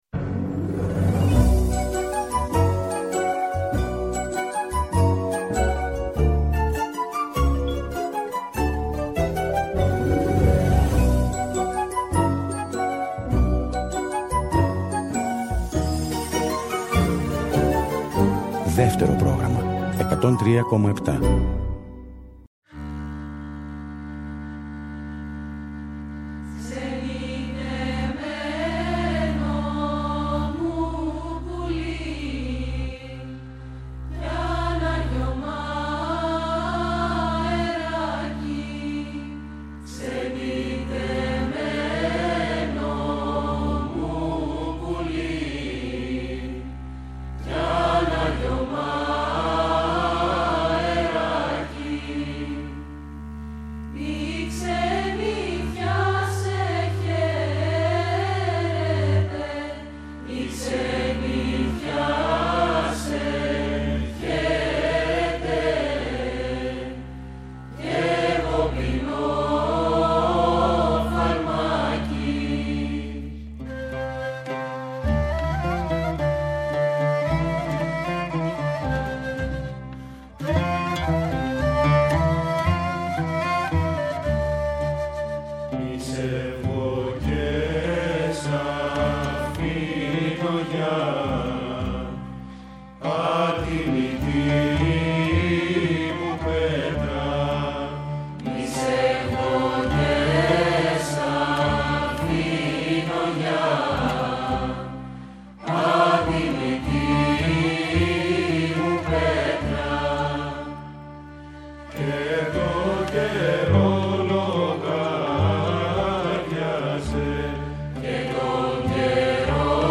Χορωδία Παραδοσιακής Μουσικής
λύρα
κοντραμπάσο
λάφτα, φωνή